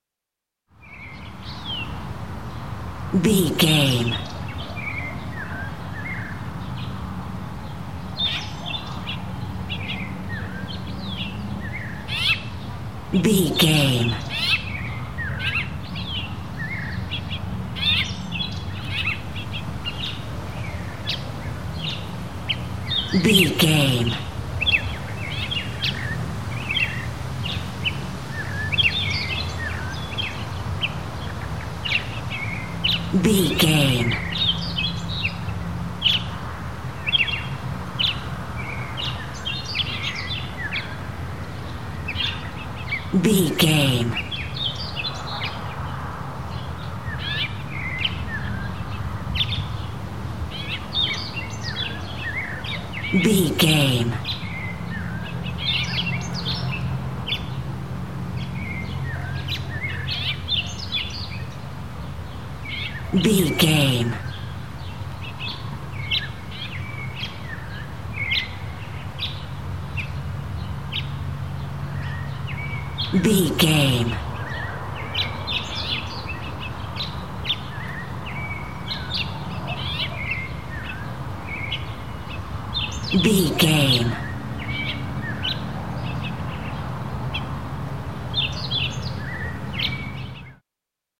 Country day birds wind
Sound Effects
nature
peaceful
ambience